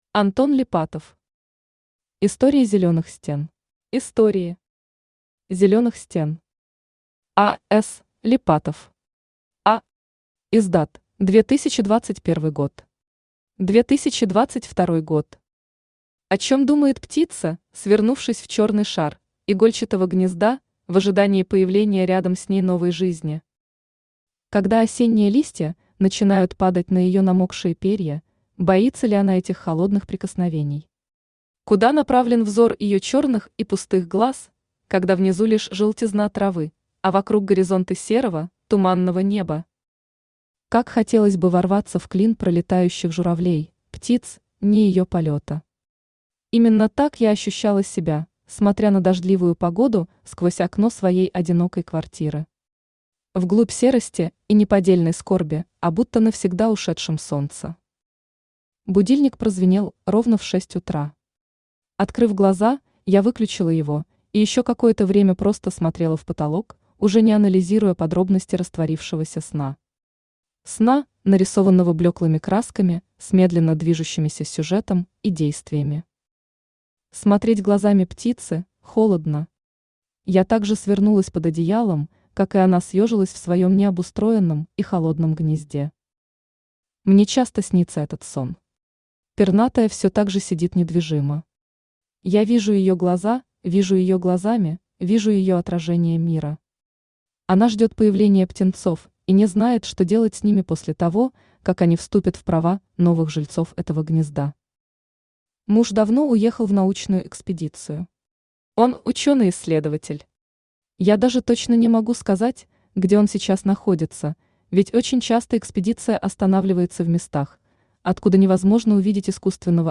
Аудиокнига Истории зелёных стен | Библиотека аудиокниг
Aудиокнига Истории зелёных стен Автор Антон Сергеевич Липатов Читает аудиокнигу Авточтец ЛитРес.